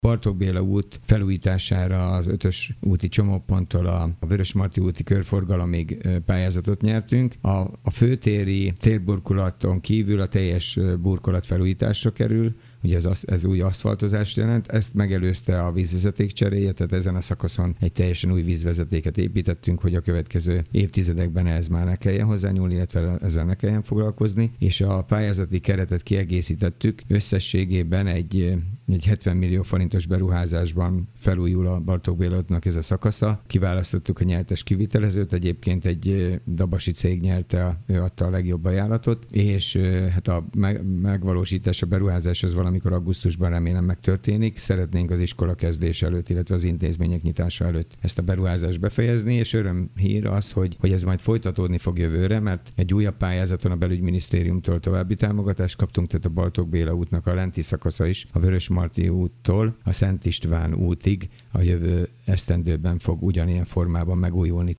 Kőszegi Zoltán polgármestert hallják.